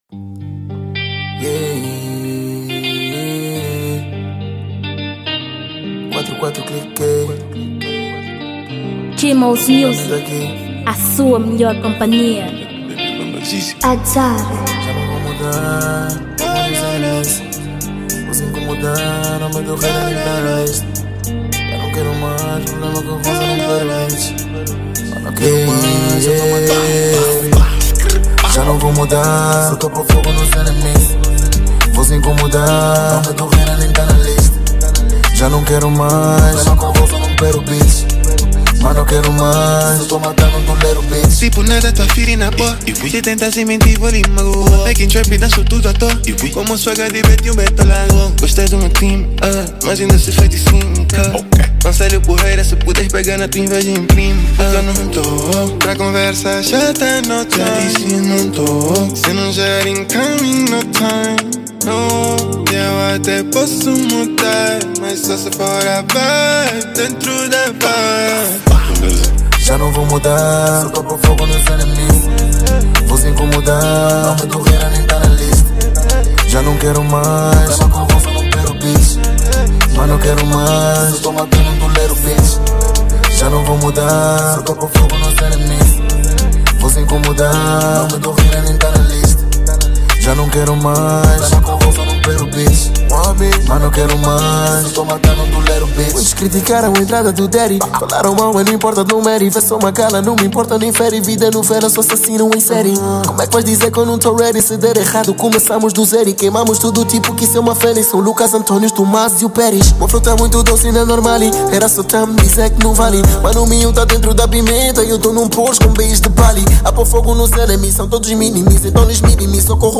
4MB/ 2023 / Trap